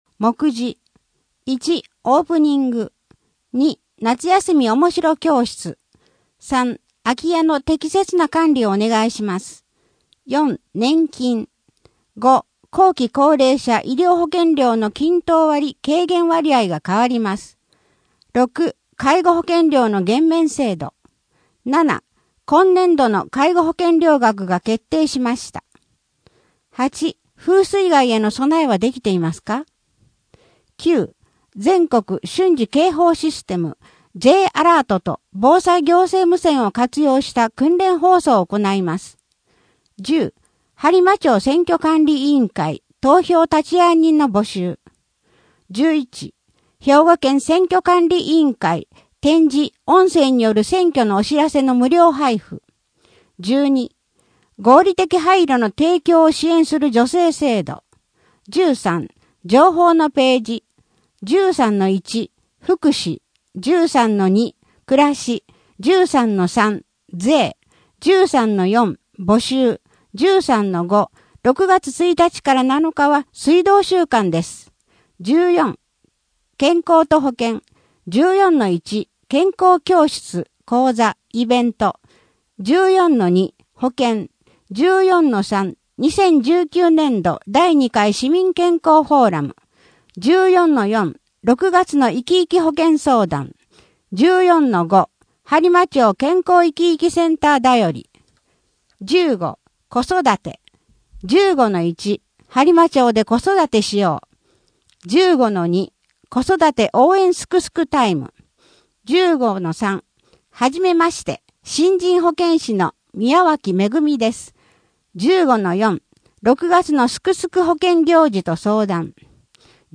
声の「広報はりま」6月号
声の「広報はりま」はボランティアグループ「のぎく」のご協力により作成されています。